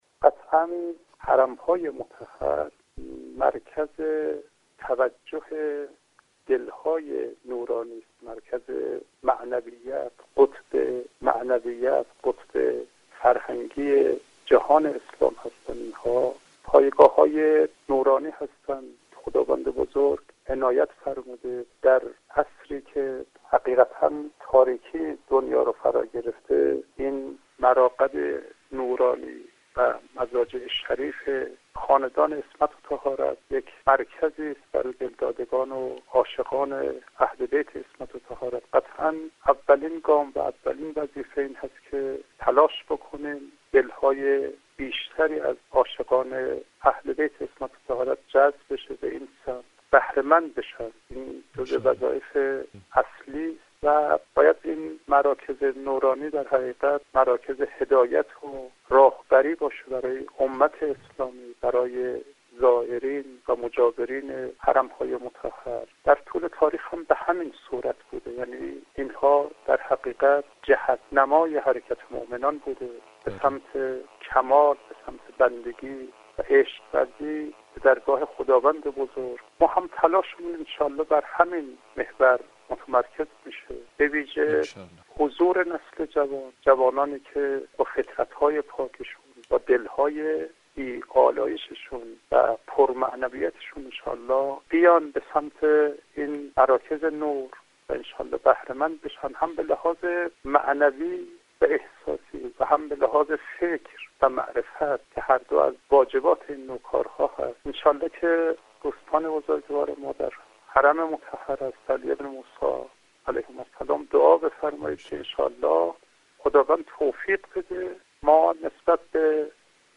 به گزارش رادیو زیارت، حجت‌الاسلام والمسلمین ابراهیم کلانتری،تولیت آستان مقدس حضرت احمد بن موسی (ع) در گفتگو با برنامه حرم تا حرم رادیو زیارت افزود : حرم های مطهر اهل بیت(ع) قطب معنویت و فرهنگی جهان اسلام و پایگاه های نورانی و مراکز هدایت برای امت اسلام و زائران و مجاوران است.